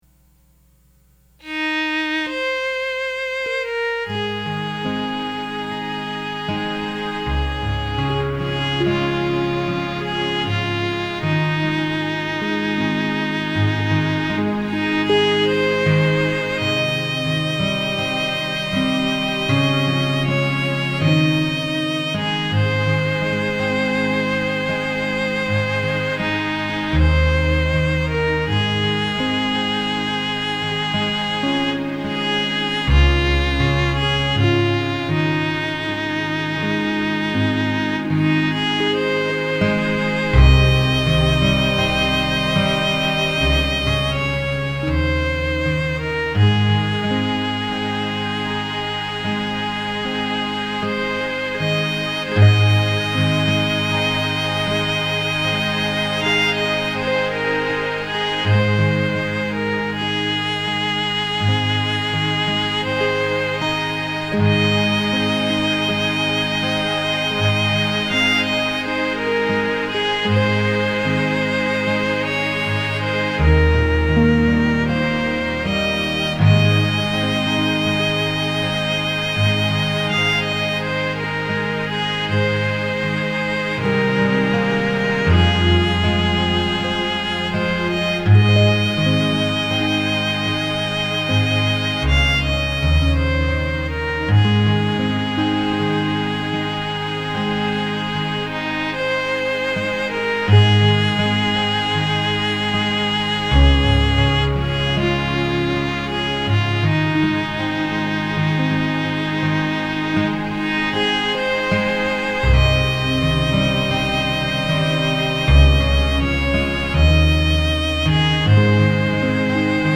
hymns played on the Harp with background music to include
violin, flute and orchestra.